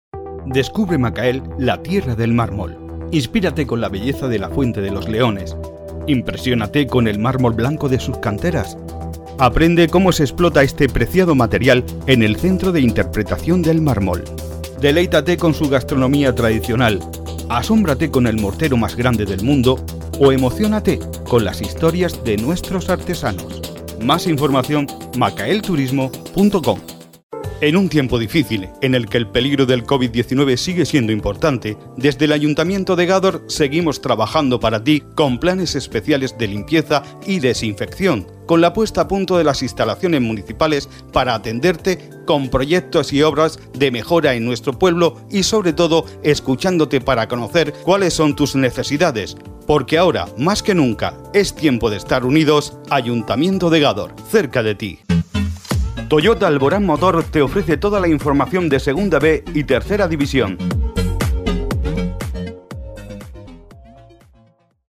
Sprechprobe: Werbung (Muttersprache):
I am an active professional speaker, native in Spanish, with my own studio.